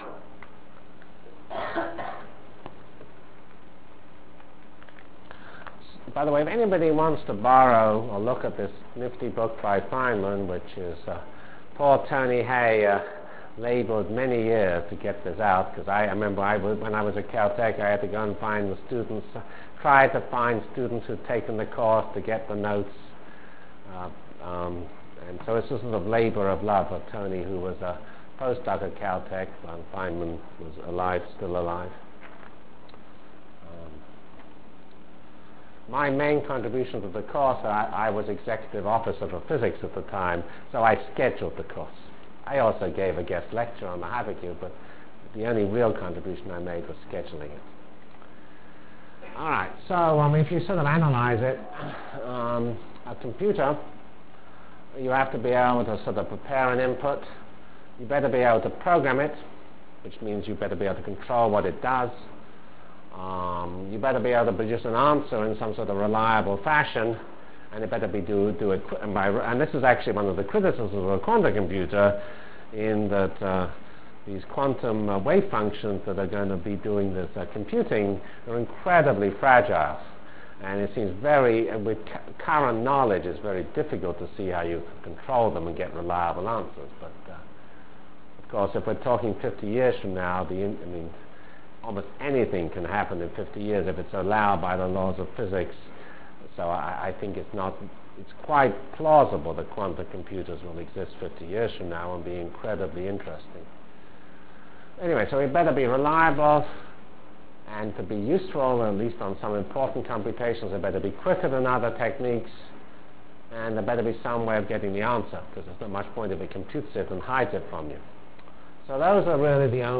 From CPS615-Lecture on Performance(end) and Computer Technologies(start)